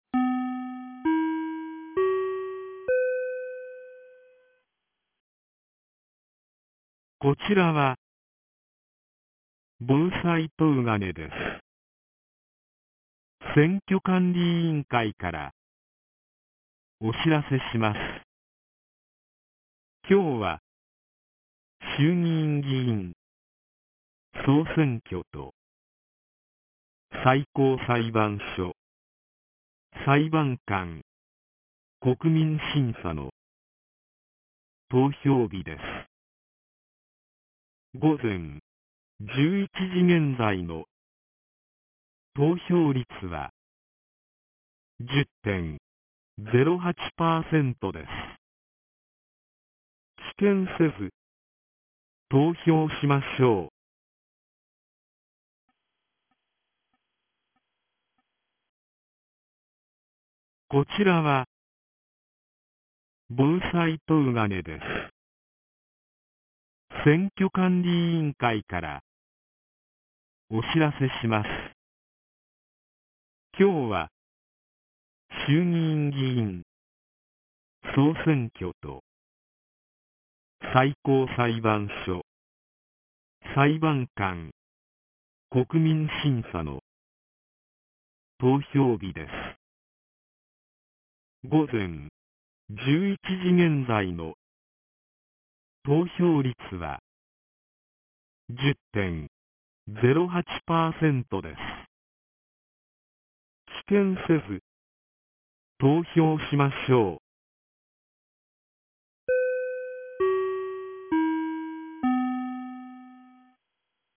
2024年10月27日 11時12分に、東金市より防災行政無線の放送を行いました。